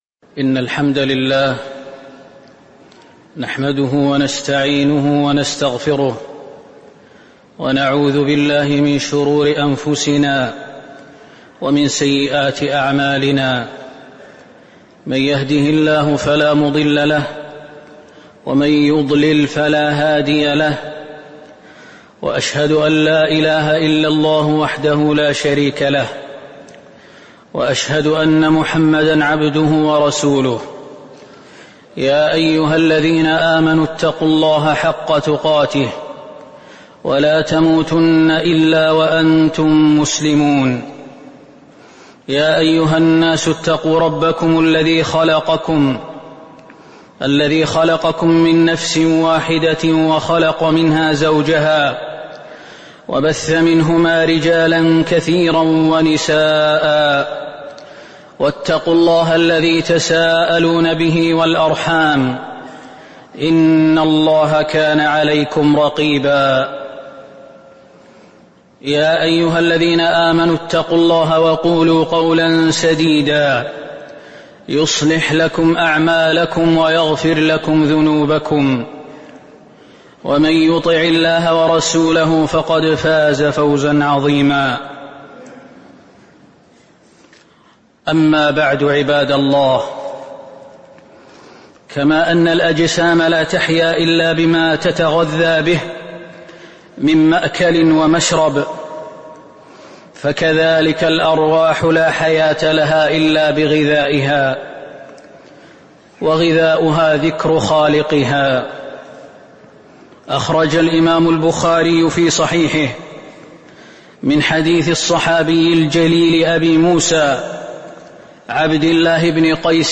خطبة من فضائل الذكر والتسبيح وفيها: حياة الروح في ذكر الله، والتسبيح من أشرف عبادة الذكر وفيه: عظم ذكر سبحان الله وبحمده، ومستحثات الإنسان على الإكثار منه
تاريخ النشر ١٥ جمادى الأولى ١٤٤٤ المكان: المسجد النبوي الشيخ: فضيلة الشيخ خالد المهنا فضيلة الشيخ خالد المهنا من فضائل الذكر والتسبيح